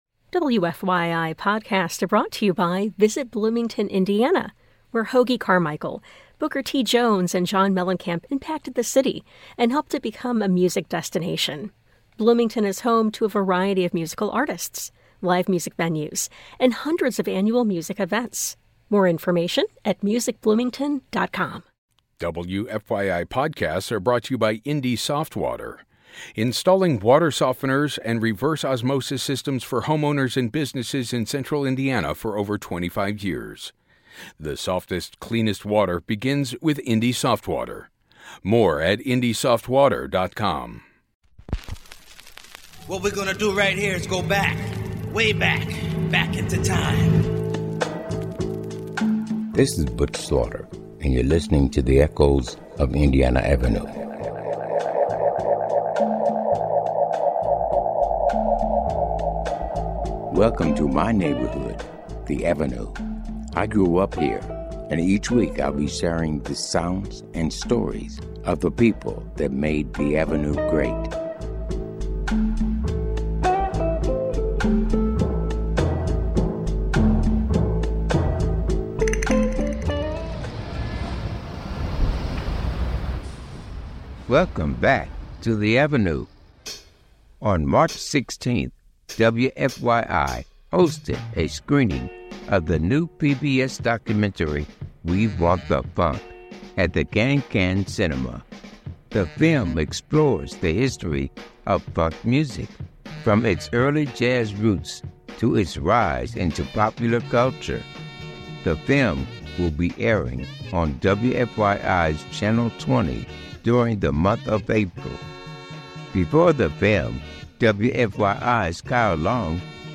A night of groove, joy, and history at the Kan-Kan